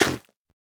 Minecraft Version Minecraft Version latest Latest Release | Latest Snapshot latest / assets / minecraft / sounds / block / nylium / break4.ogg Compare With Compare With Latest Release | Latest Snapshot